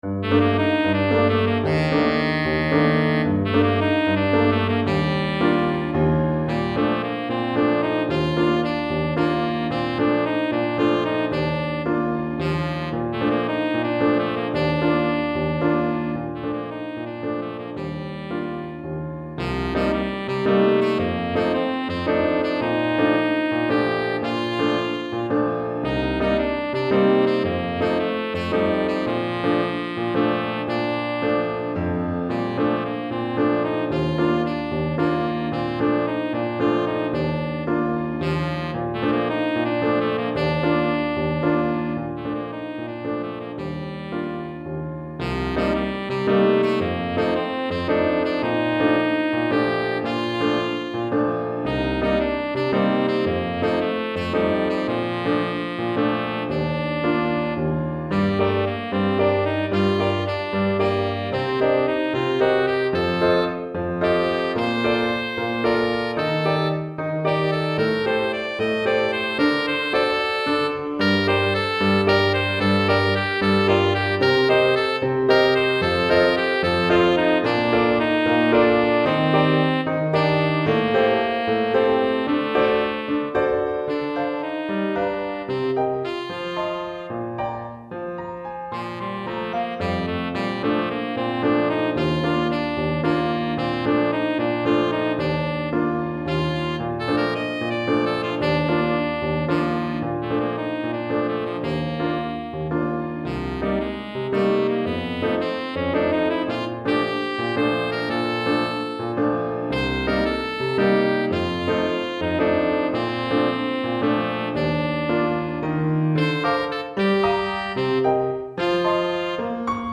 Saxophone Ténor et Piano